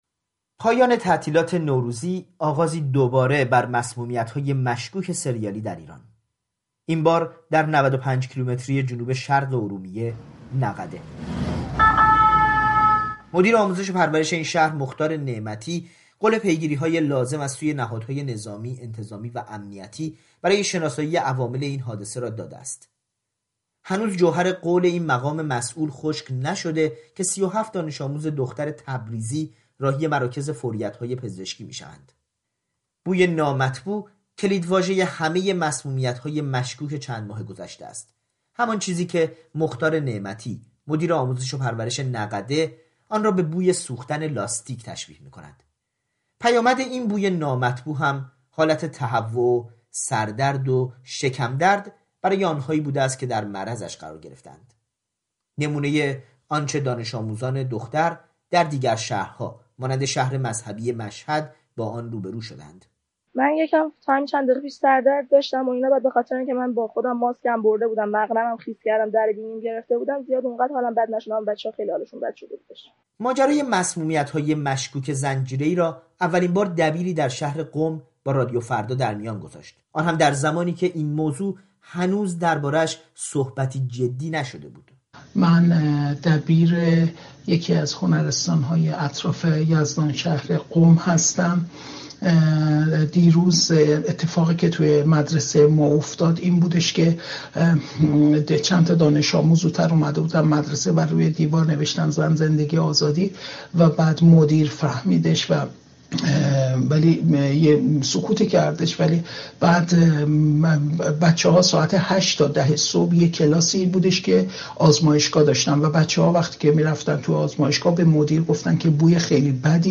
گزارشی